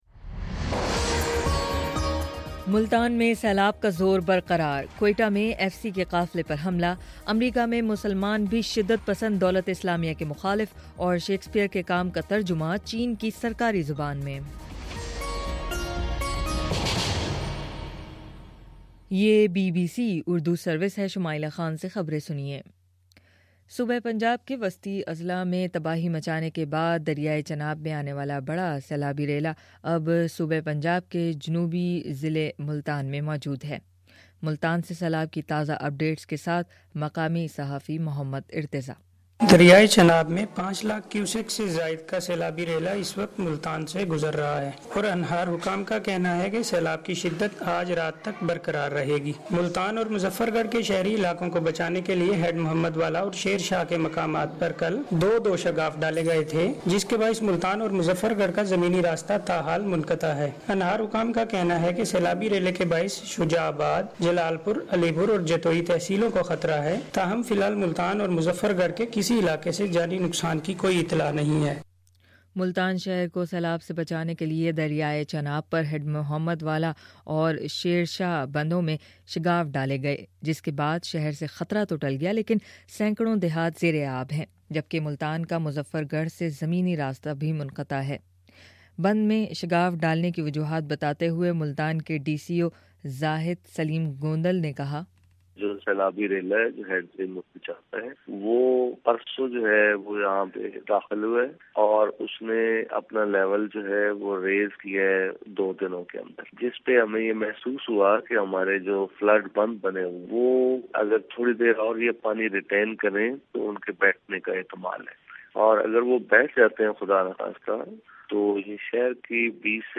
ستمبر13: صبح نو بجے کا نیوز بارہبُلیٹن